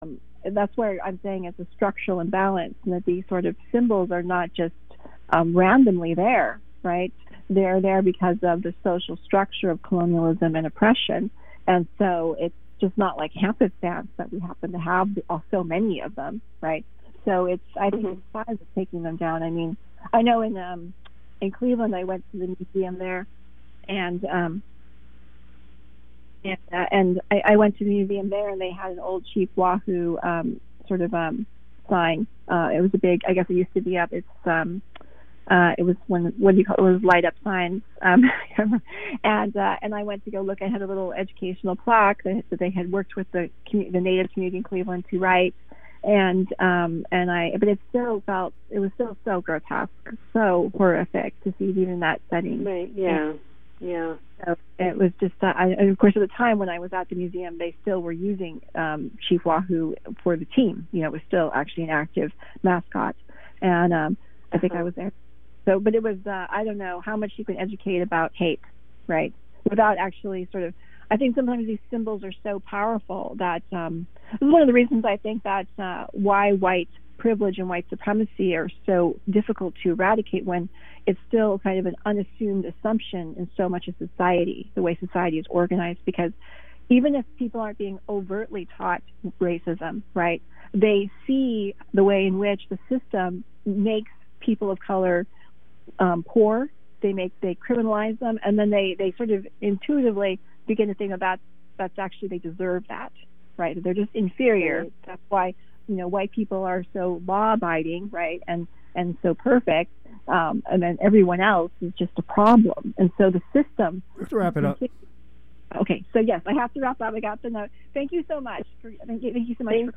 Download audio file The Seekers, is the first in a two-part documentary series that examines the experiences of Central American migrants seeking asylum in the US. El Salvador, Honduras, and Guatemala are among the most dangerous countries in the world for women.